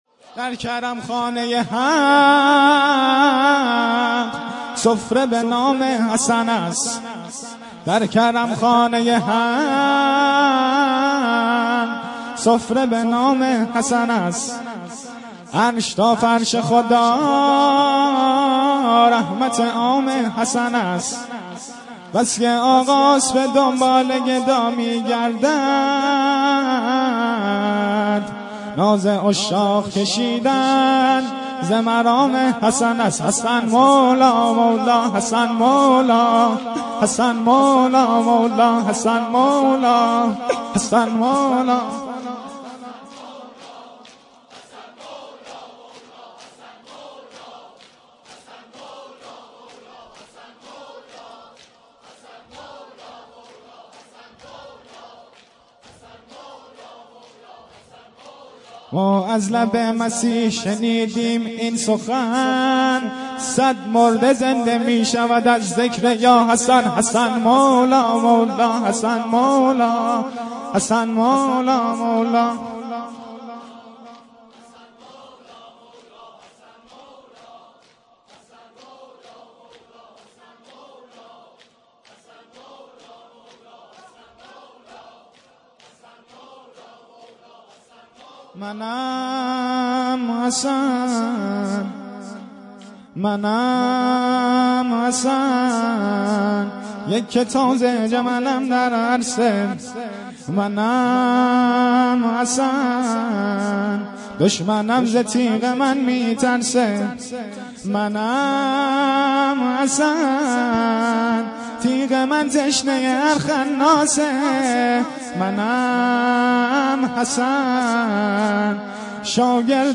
شور‌
شب ششم محرم ۱۴۰۱